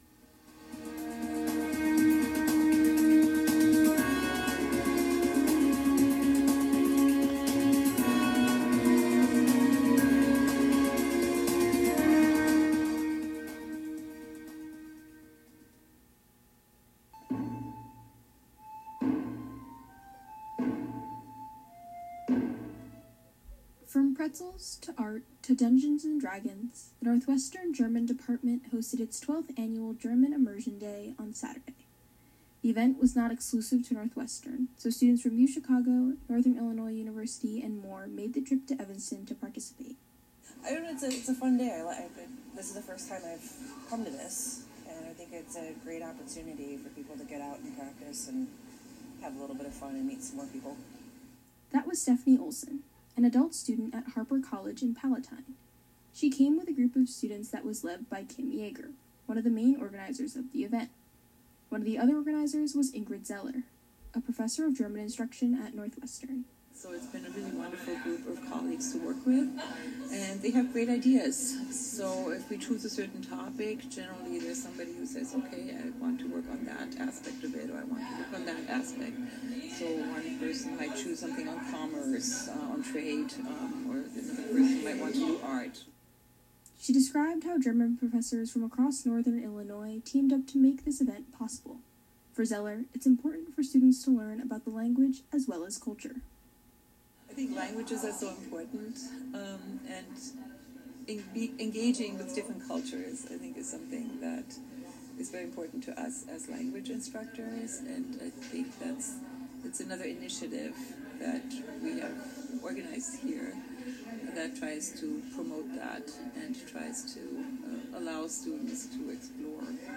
May 2, 2025: Inside German Immersion Day, the new Waa-Mu and the new ice bucket challenge, and a Concert Countdown. WNUR News broadcasts live at 6 pm CST on Mondays, Wednesdays, and Fridays on WNUR 89.3 FM.